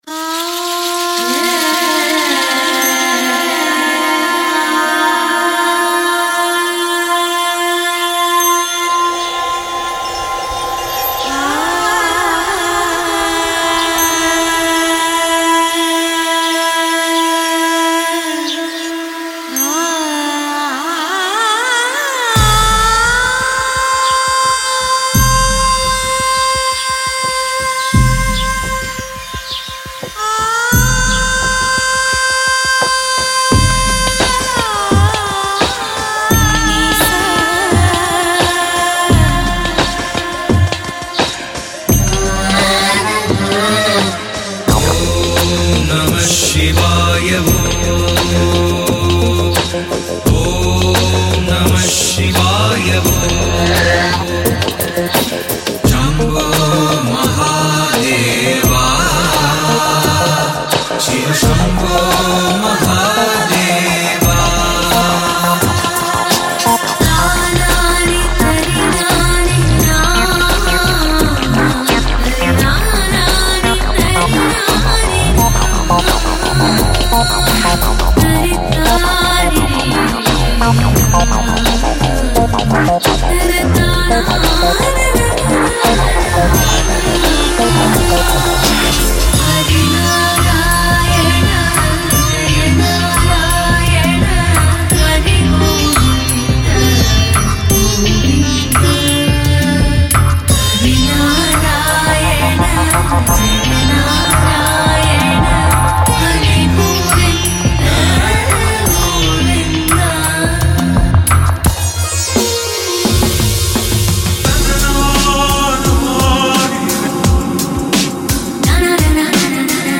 • 74女声循环
• 41合奏声带
• 40个男性声带